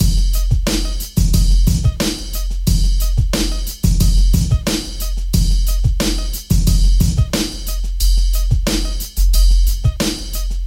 描述：嘻哈鼓
Tag: 90 bpm Hip Hop Loops Drum Loops 1.80 MB wav Key : Unknown